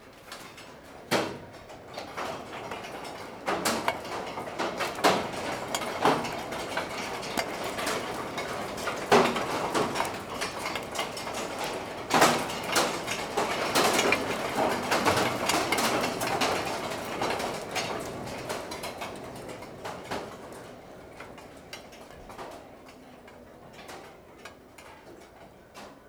Lluvia de granizo sobre tejado
Grabación sonora del momento en la tormenta en el que cae una breve lluvia de granizo sobre el tejado, produciendo el sonido de golpeo característico
Sonidos: Agua
Sonidos: Naturaleza